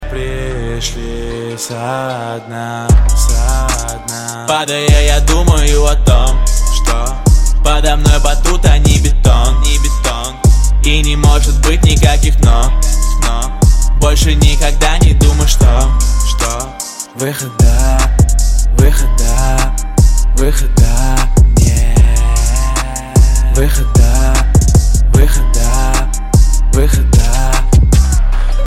• Качество: 128, Stereo
громкие
Хип-хоп